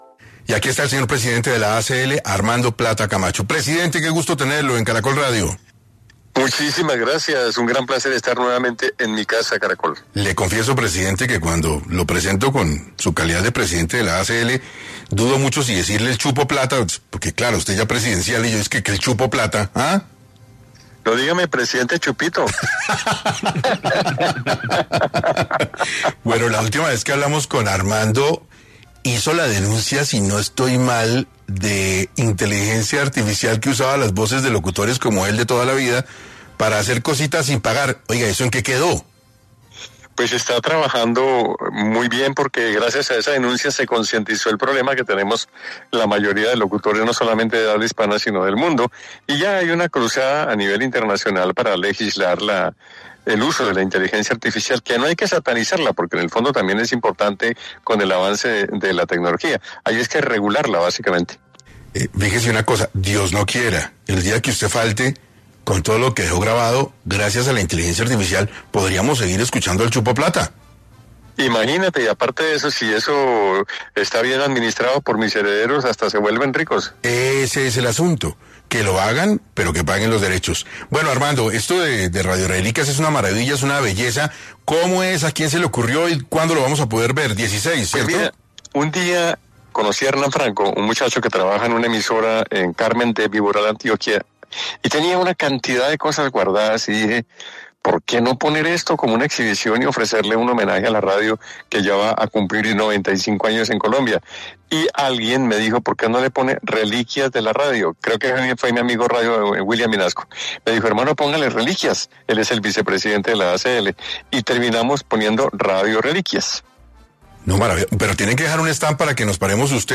Armando Plata, periodista, locutor y miembro de la Asociación Colombiana de Locutores (ACL), pasó por los micrófonos de 6AM Hoy por Hoy, para presentar ‘Radio Reliquias’, una exposición sobre la historia e importancia de la radio, que cumplirá 95 años en Colombia: